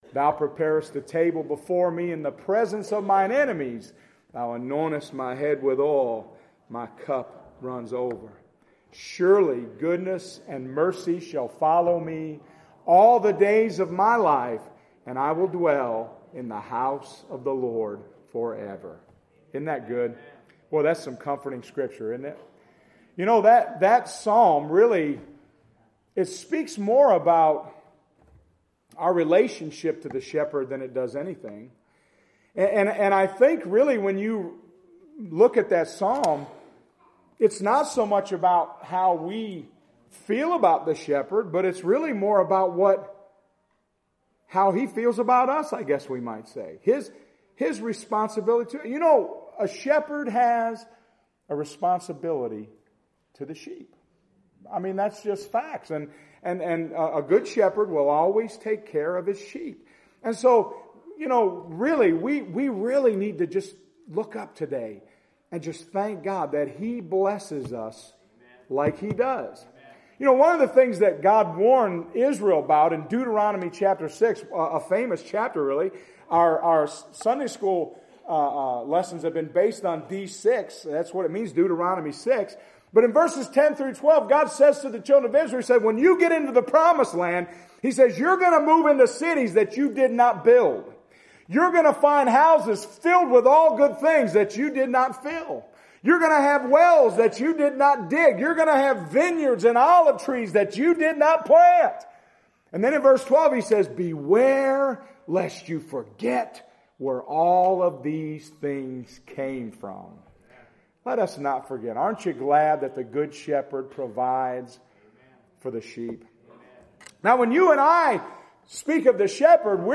Sermon Audio — Unity Free Will Baptist Church